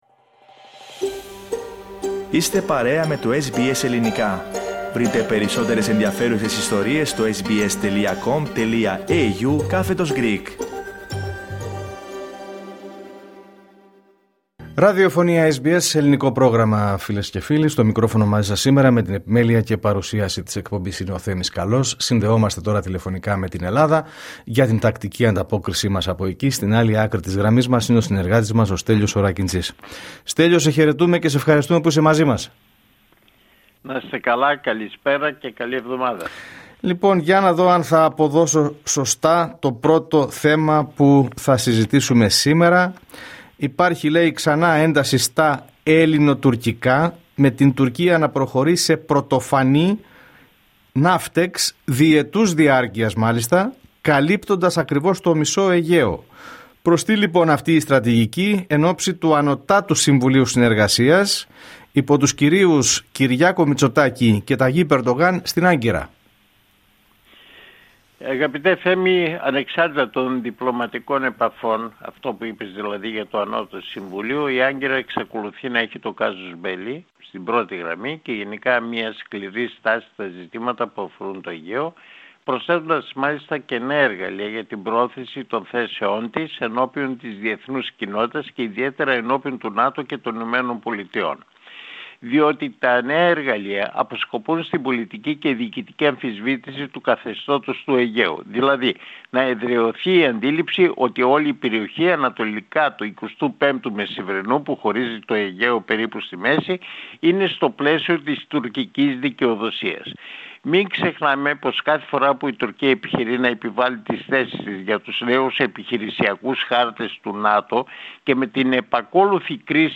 Ακούστε την εβδομαδιαία ανταπόκριση από Ελλάδα